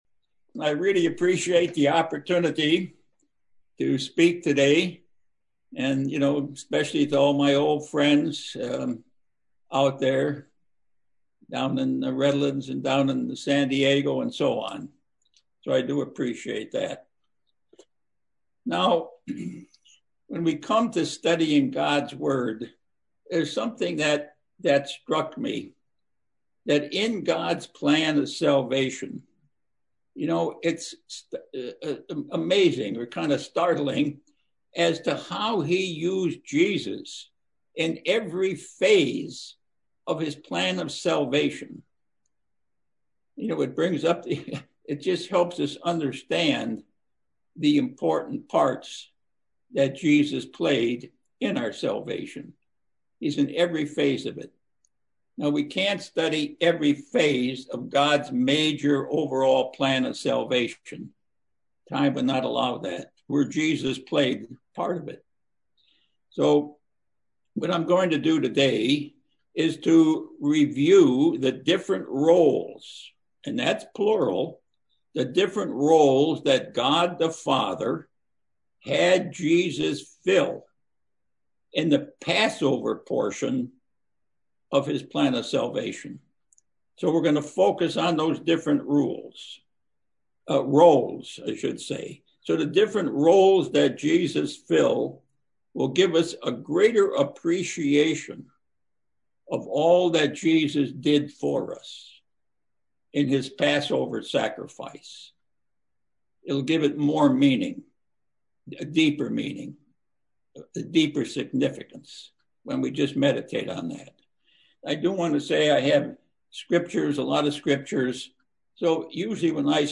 Given in Las Vegas, NV Redlands, CA San Diego, CA